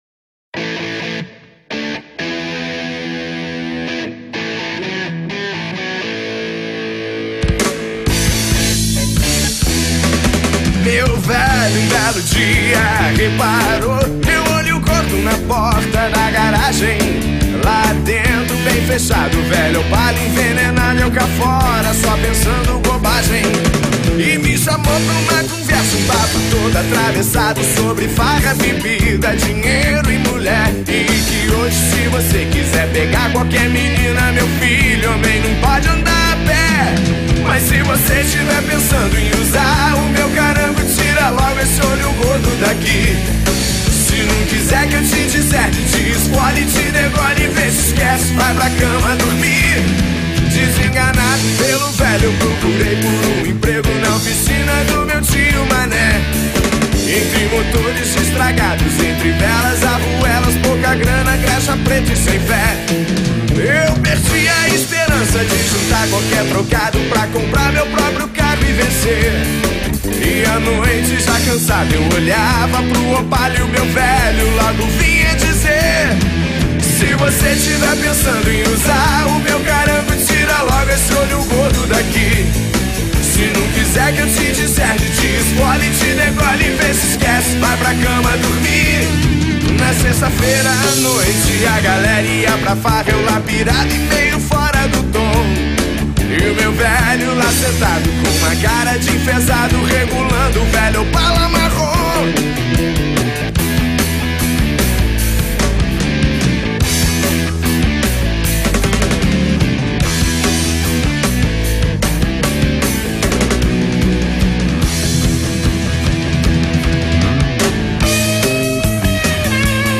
Rock & Roll – 2007
aos poucos conquistar seu espaço com sua música vibrante